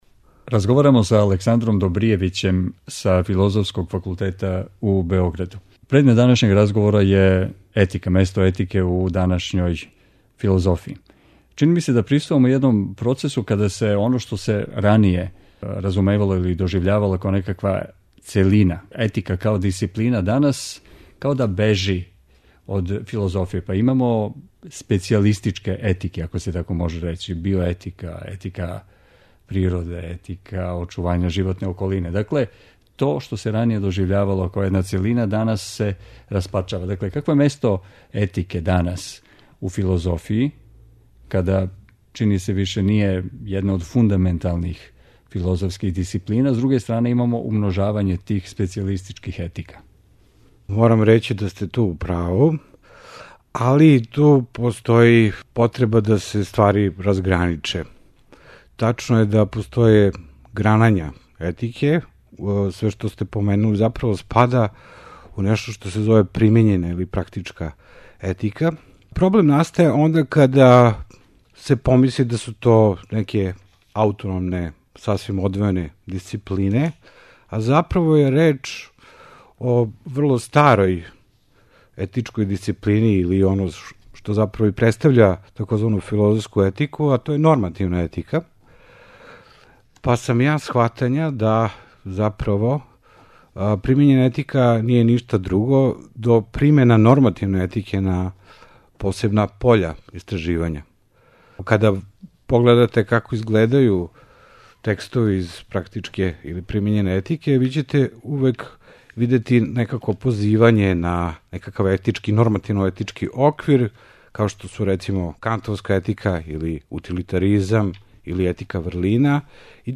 Дијалози